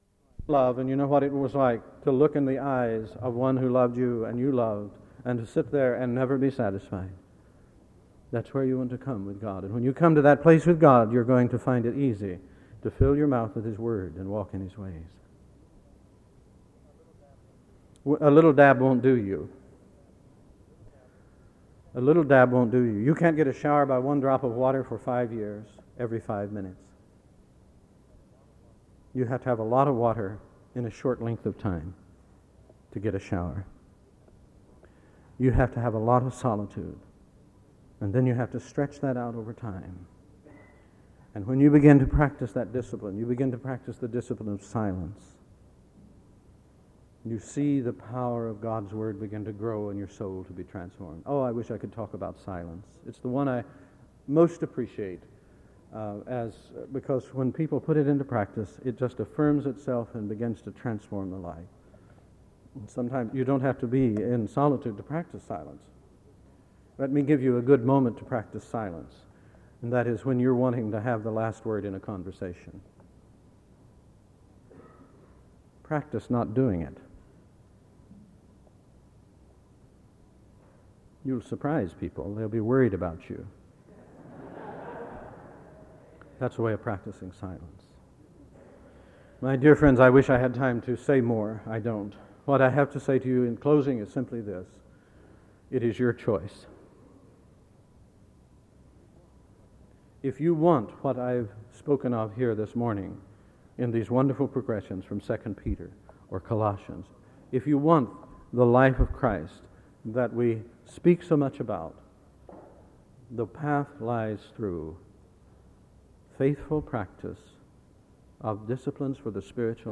SEBTS Page Lecture - Dallas Willard September 27, 1995
• Wake Forest (N.C.)